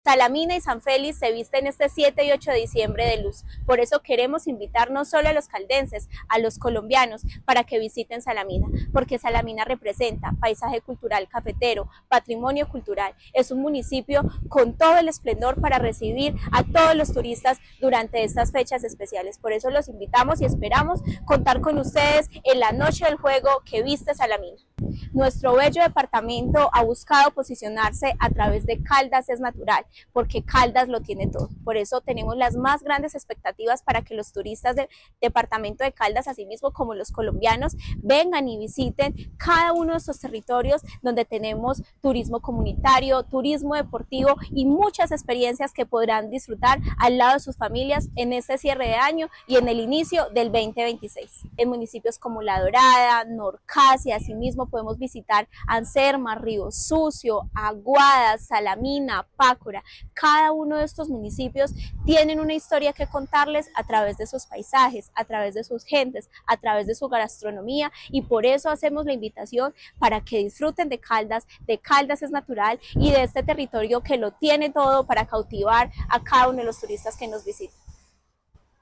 Tania Echeverry Rivera, secretaria de Desarrollo, Empleo e Innovación.